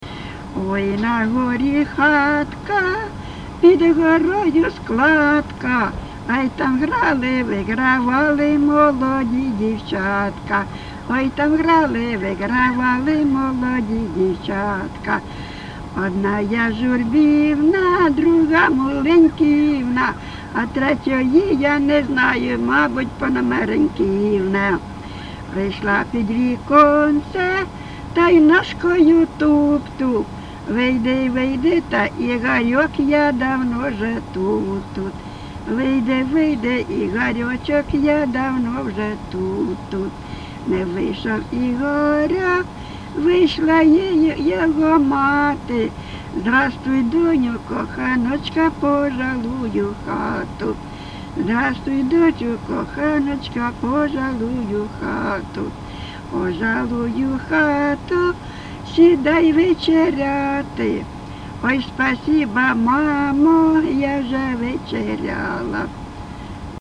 ЖанрЖартівливі
Місце записус. Привілля, Словʼянський (Краматорський) район, Донецька обл., Україна, Слобожанщина